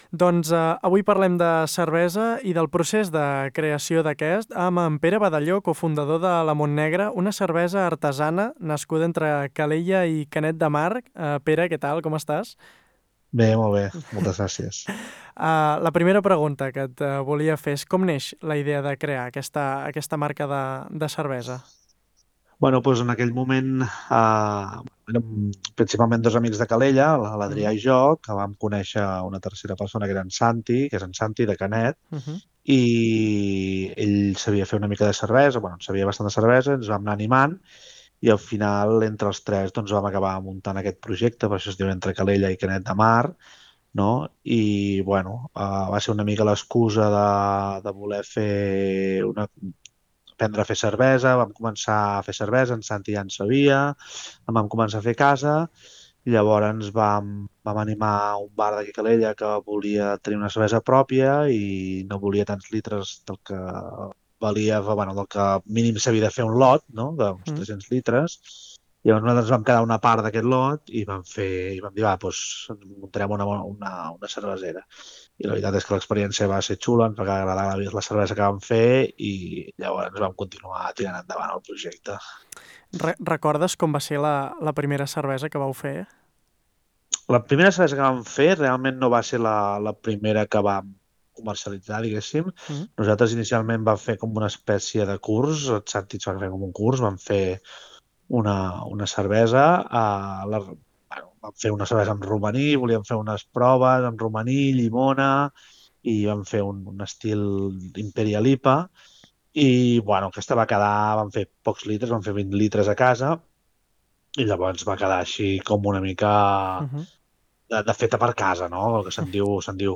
ENTREVISTA-COMPLETA.mp3